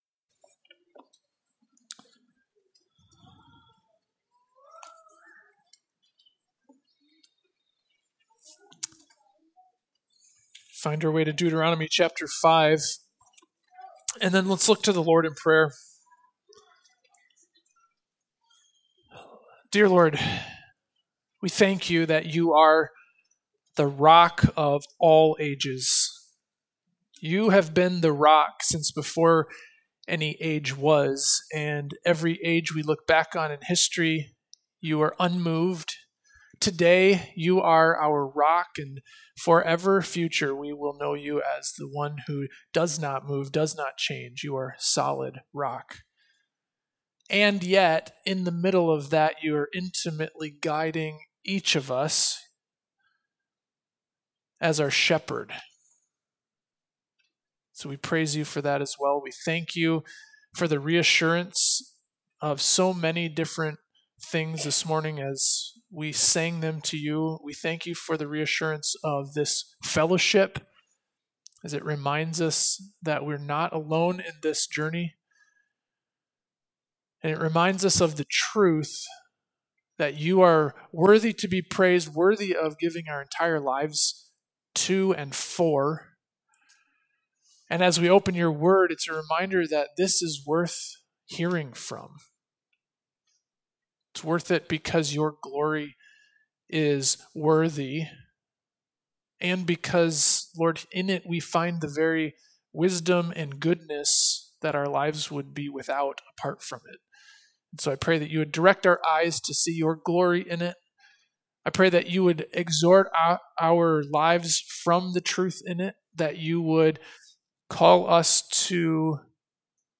Sermons :: Faith Baptist Church